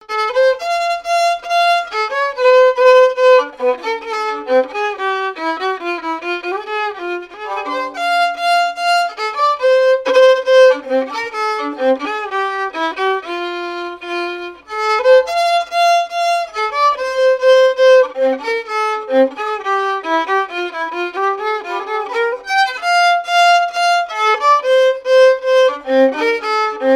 Marche nuptiale n° 5
marches de noces jouées aux Gueurnivelles
Pièce musicale inédite